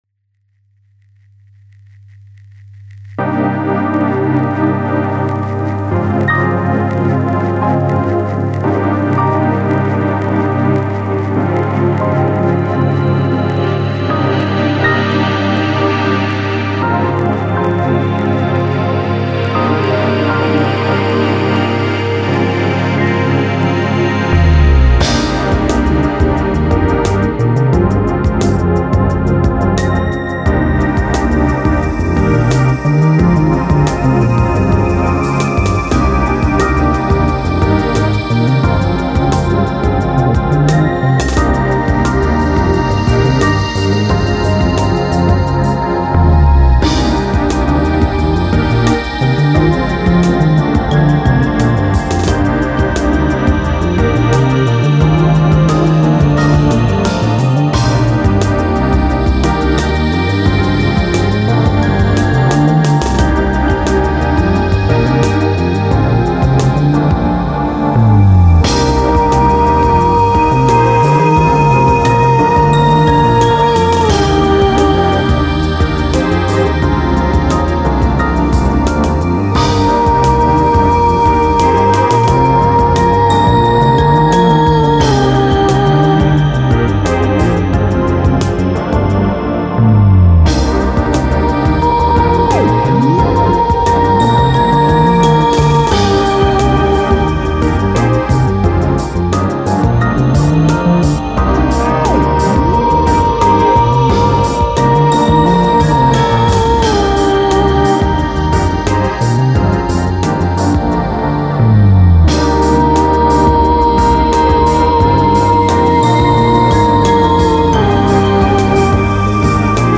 08年1月下旬に出る予定のＣＤに入っている曲を先にリミックスするという暴挙。
しかし跡形もないぐらいに変えているので、聴いても分からないかも知れません。